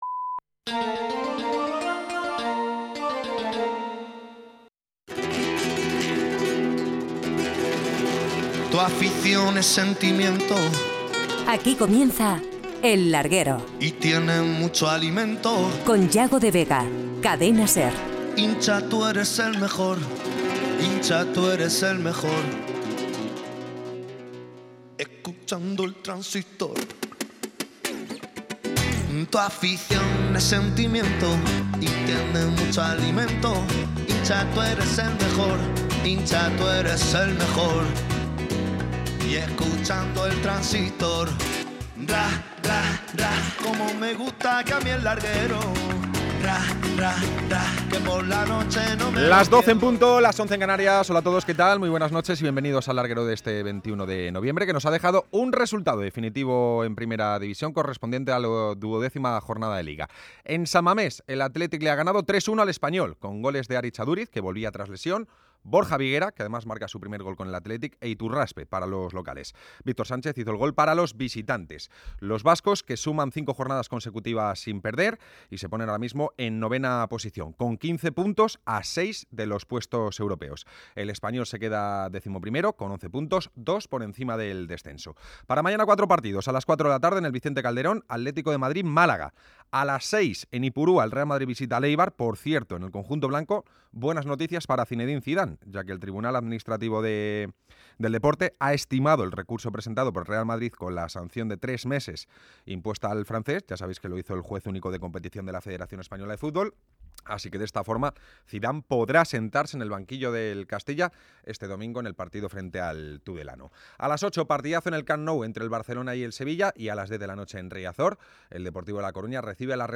De la p�gina de Alex en FB: Os dejamos el audio de la entrevista de Alex Pella en el programa El Larguero.